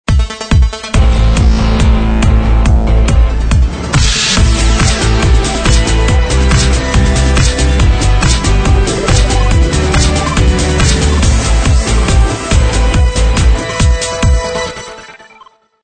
描述：舞蹈脉搏，电子 riff，失真吉他，低音神童，伟大的过渡，感情的旋律